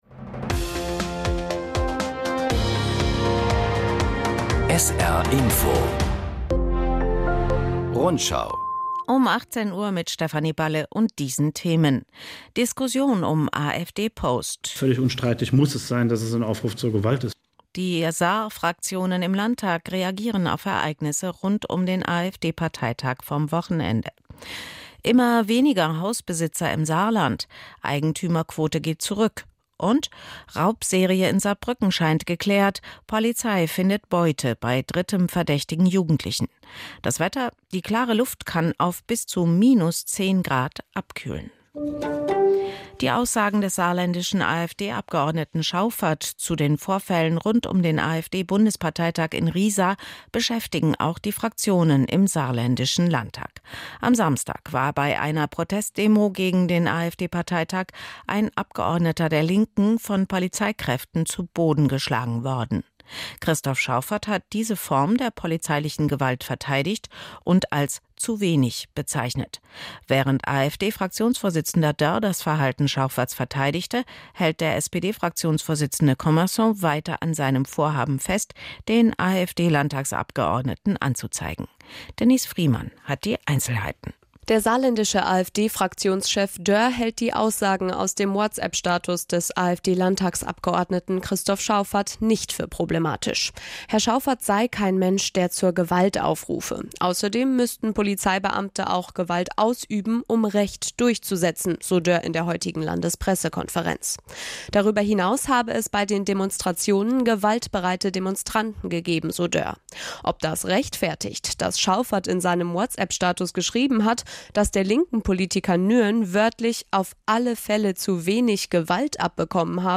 … continue reading 7 つのエピソード # Nachrichten